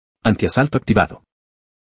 Ademas de las ventanas emergentes se recomienda agregar una alerta de sonido para identificar tanto visualmente de manera sonora y dar seguimiento mas oportuno a escenarios criticos.